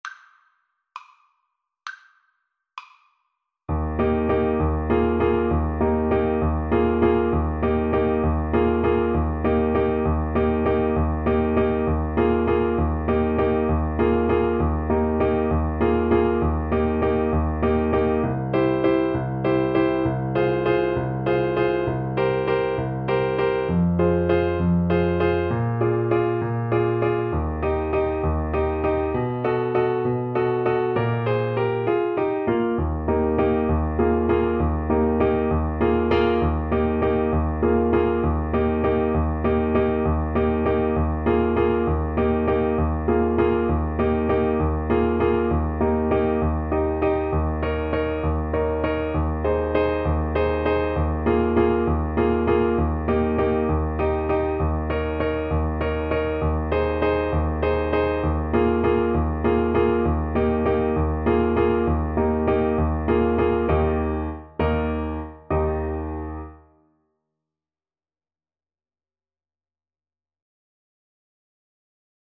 6/8 (View more 6/8 Music)
. = 66 No. 3 Grazioso
Eb major (Sounding Pitch) C major (Alto Saxophone in Eb) (View more Eb major Music for Saxophone )